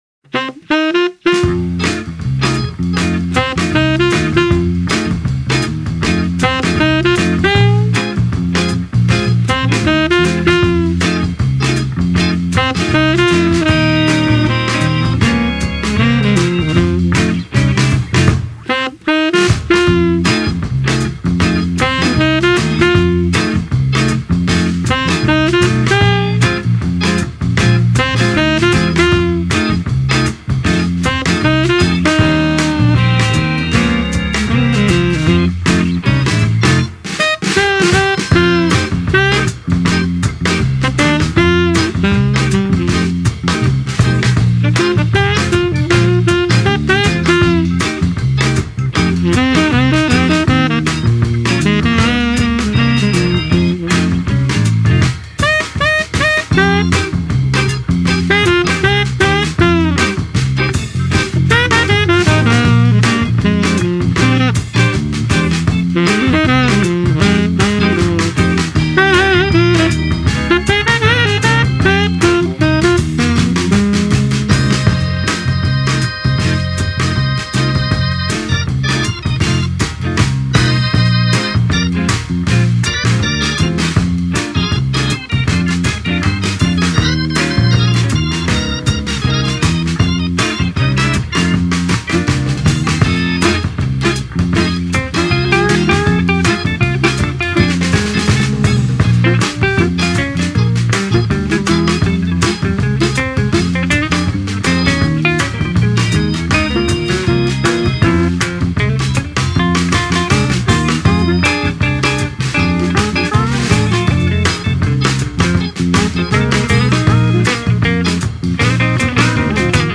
a funky instrumental